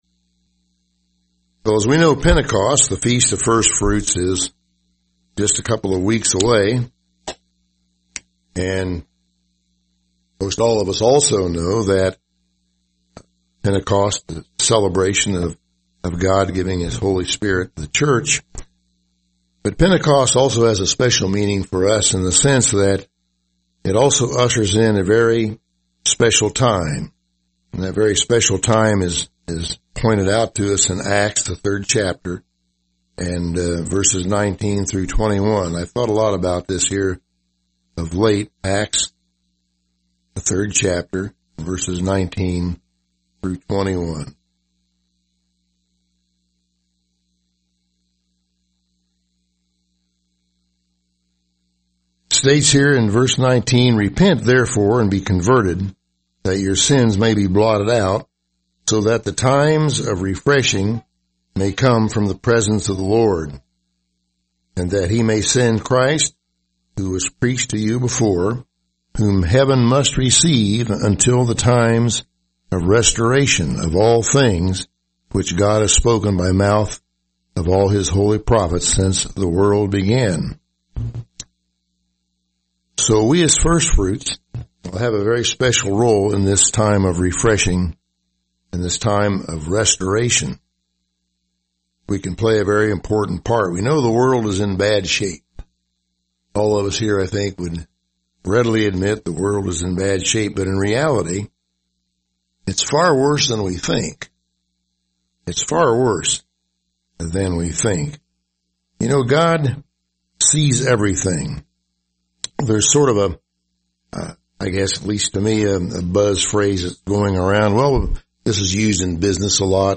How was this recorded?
Given in Indianapolis, IN